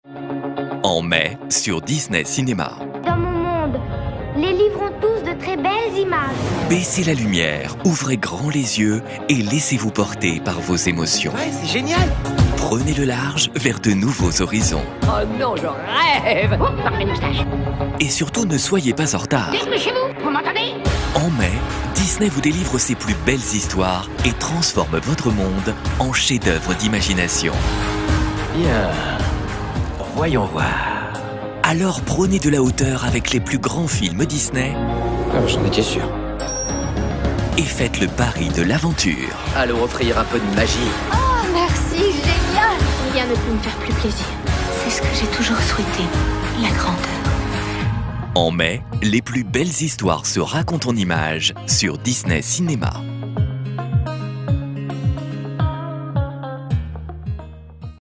Genre : voix off.